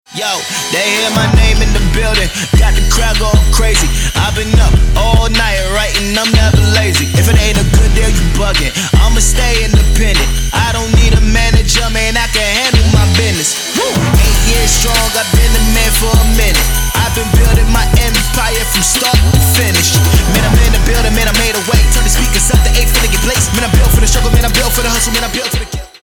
• Качество: 320, Stereo
мужской вокал
Trap
качающие
Rap
Bass
Tribal Trap